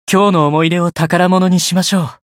觉醒语音 将今天的回忆珍藏起来 今日の思い出を宝物にしましょう 媒体文件:missionchara_voice_551.mp3